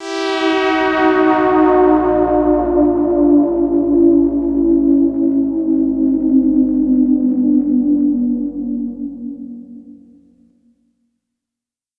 Moog Bend.wav